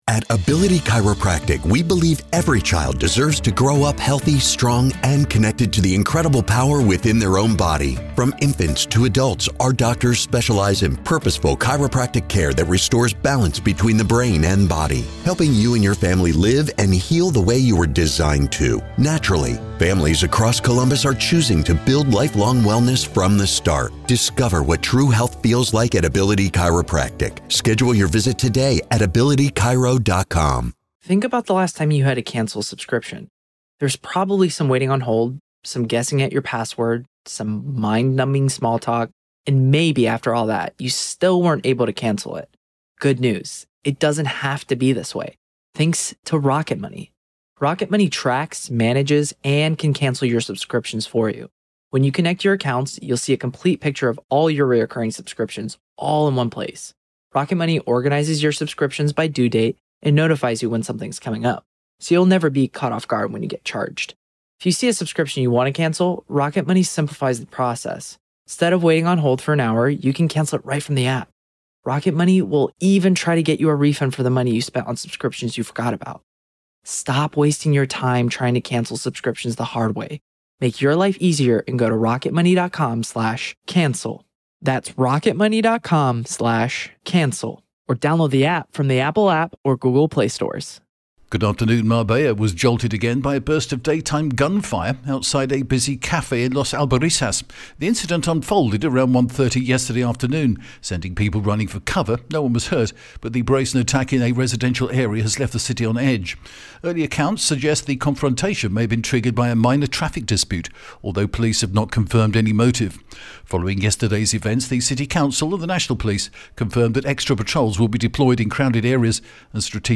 The latest Spanish news headlines in English: December 3rd 2025